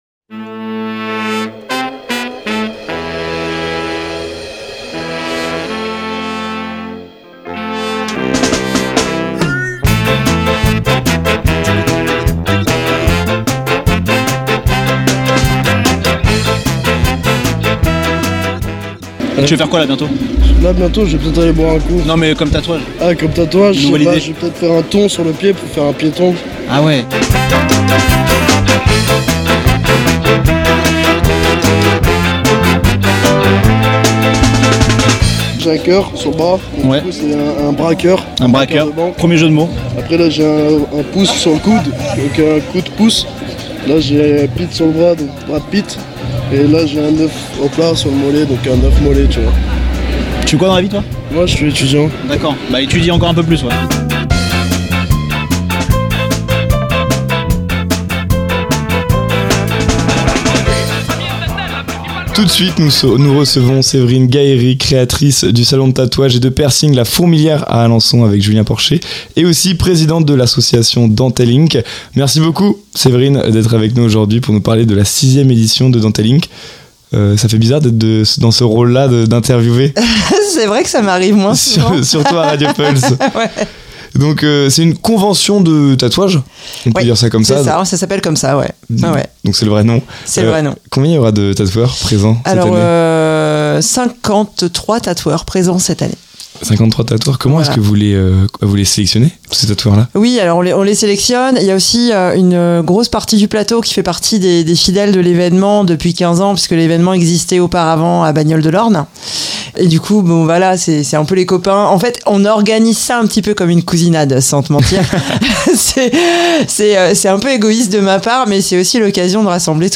Dans cette interview elle nous parle de la grande convention de tatouage qui débarque à la Halle au Blé les 13 et 14 septembre ! Deux jours pour découvrir des artistes venus de toute la France, et plonger dans l’univers du tattoo.